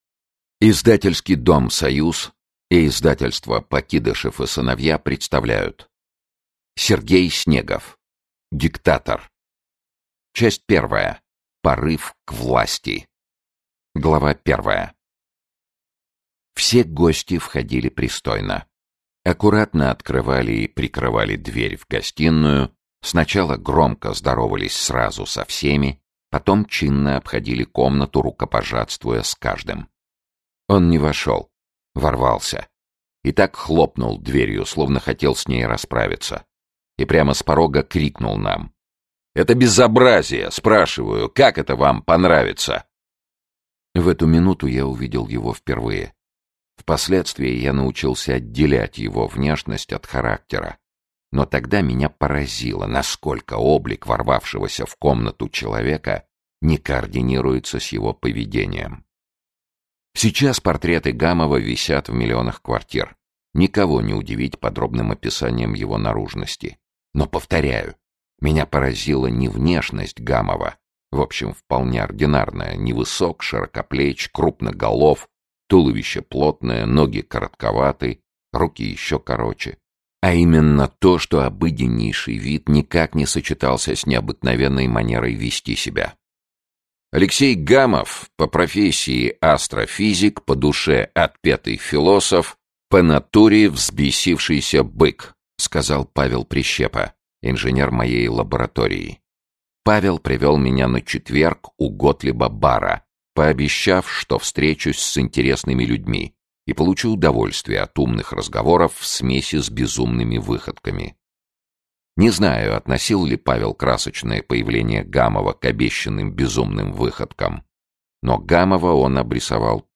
Аудиокнига Диктатор | Библиотека аудиокниг
Aудиокнига Диктатор Автор Сергей Снегов Читает аудиокнигу Сергей Чонишвили.